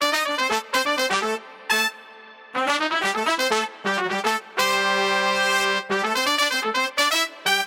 新放克 ACOUSTIC BASS 125
标签： 125 bpm Jazz Loops Strings Loops 1.29 MB wav Key : A
声道立体声